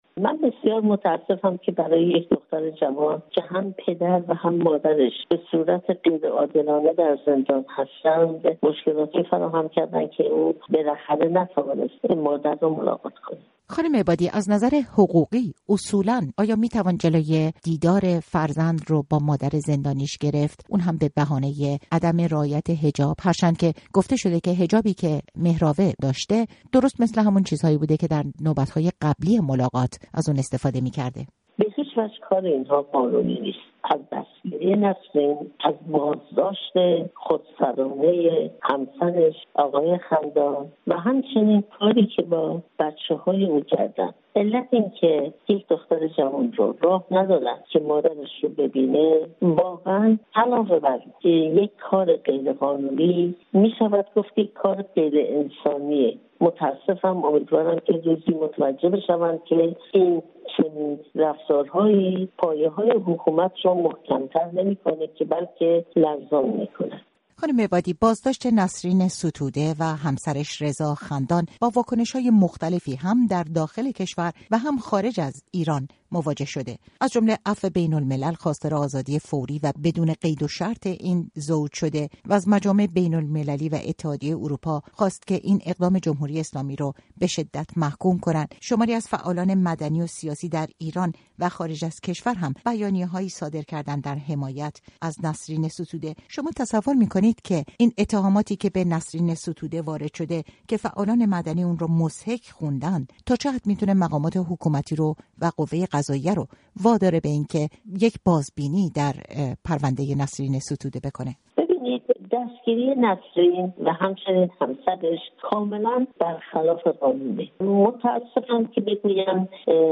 گفت‌وگو با شیرین عبادی، برنده جایزه صلح نوبل، درباره رفتار تهدیدآمیز ماموران با فرزند نسرین ستوده